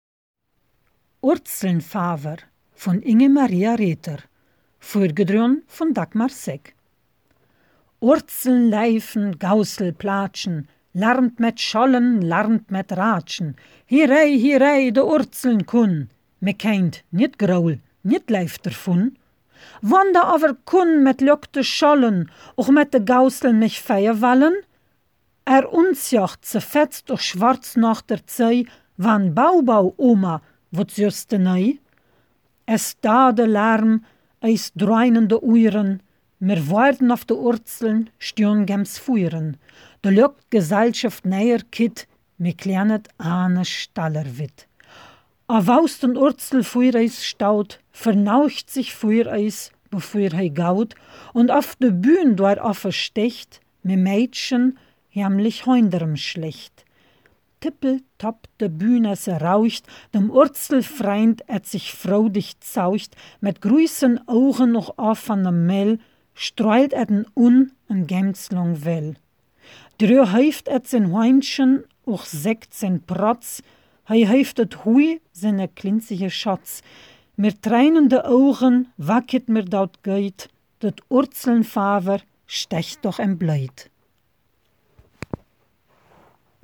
Ortsmundart: Agnetheln